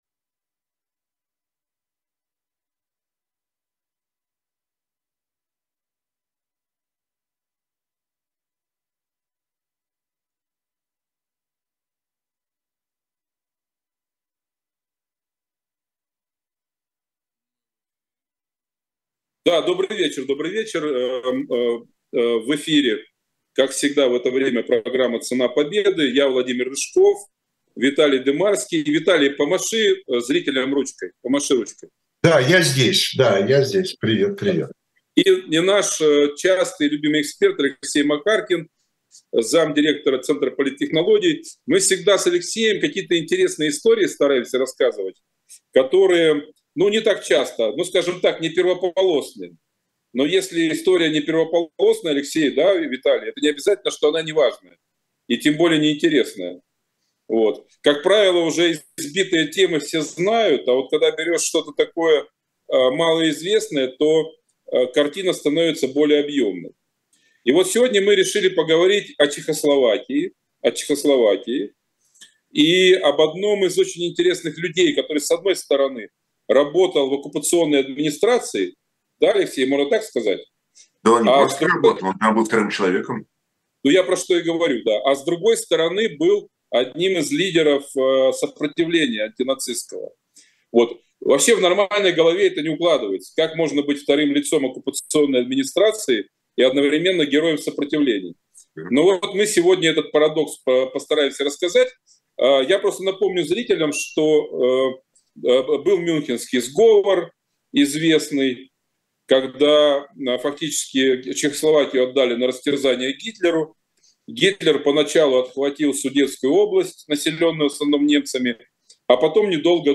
Ведущие: Виталий Дымарский и Владимир Рыжков